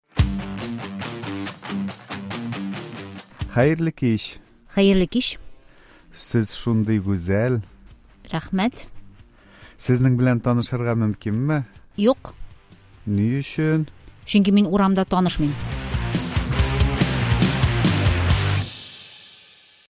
Диалог: Сезнең белән танышырга мөмкинме?